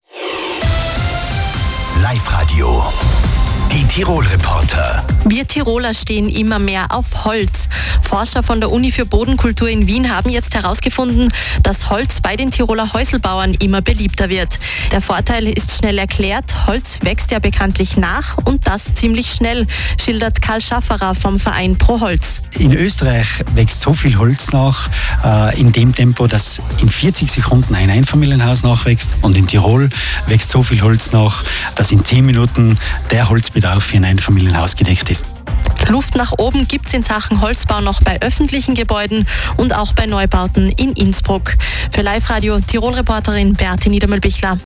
mitschnitt_life_radio_proholz.wav